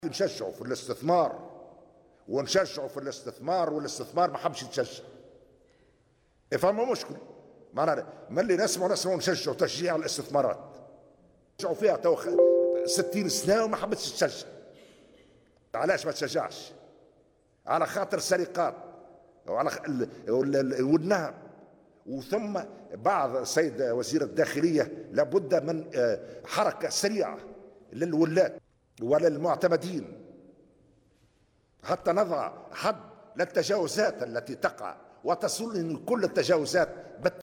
وأضاف على هامش إشرافه اليوم على اجتماع مجلس الوزراء، أن كل التجاوزات تصله و بالتفاصيل.